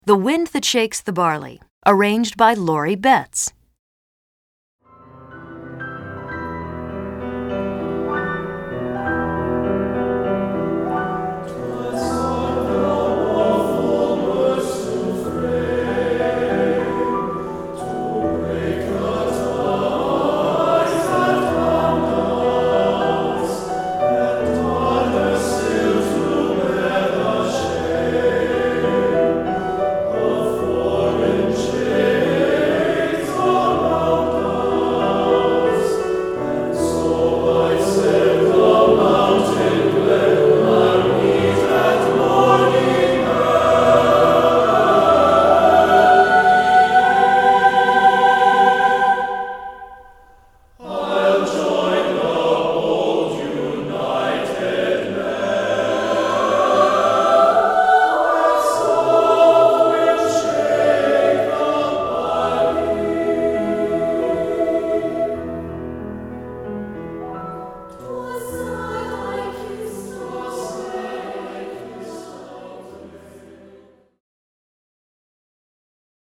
Composer: Traditional Irish
Voicing: SATB